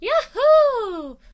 peach_yahoo5.ogg